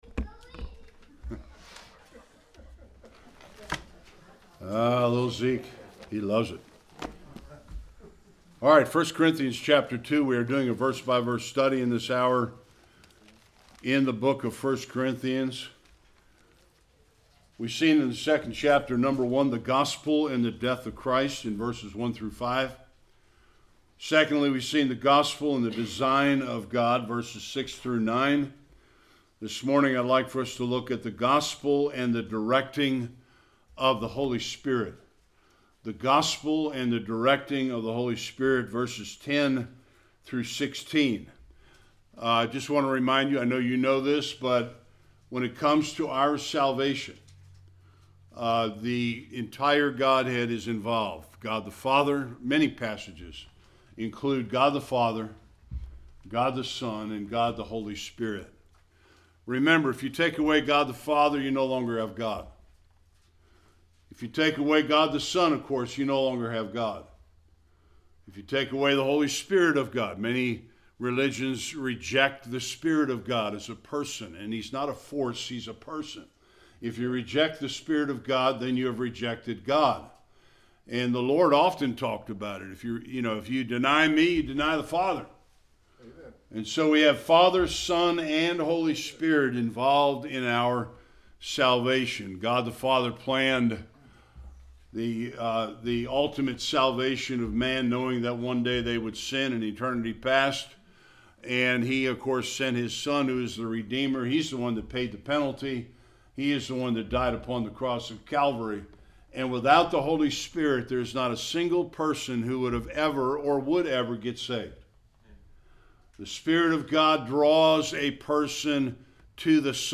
10-16 Service Type: Sunday Worship 4 important ministries of the Holy Spirit.